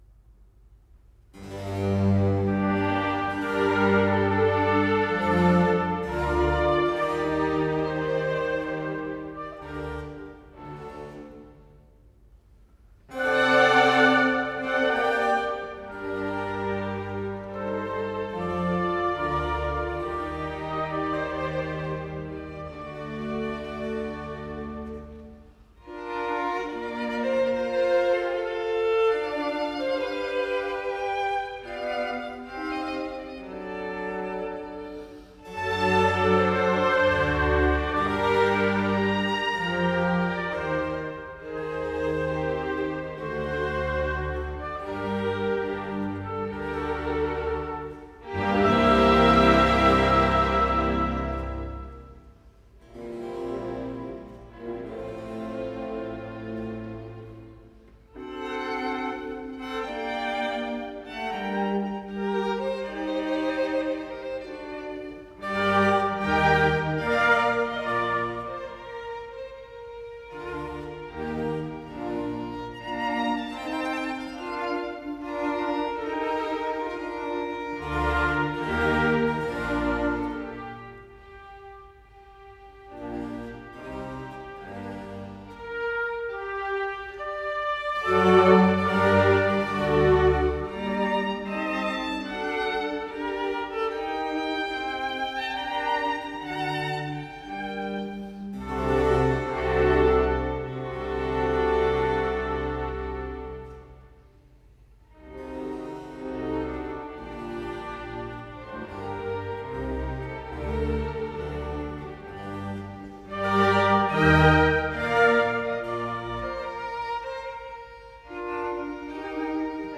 G minor - Largo affettuoso